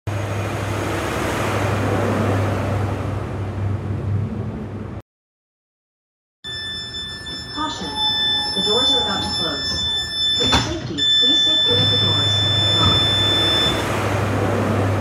🚆 Door Closing Sound USA Sound Effects Free Download